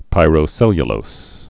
(pīrō-sĕlyə-lōs, -lōz)